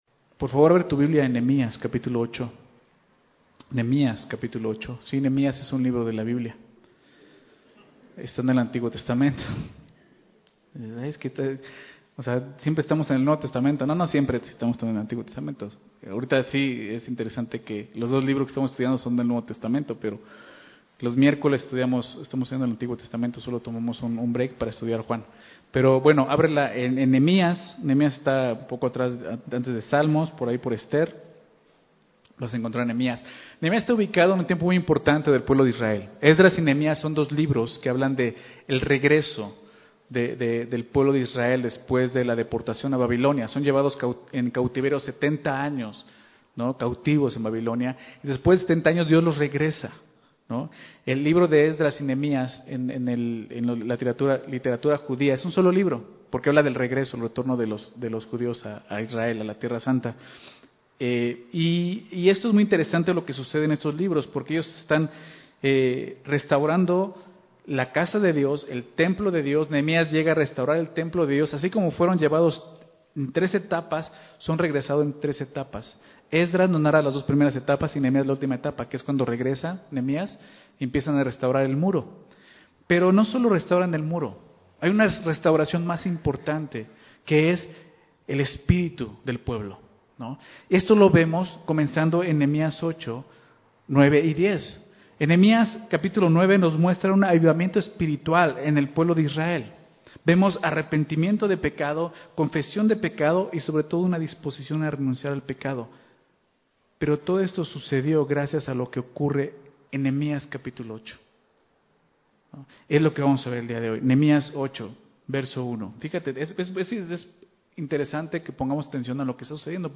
Enseñanza
002_Ensenanza.mp3